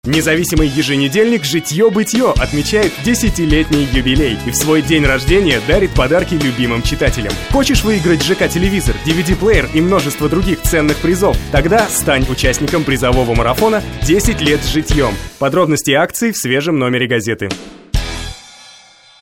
Запись голоса для этого ролика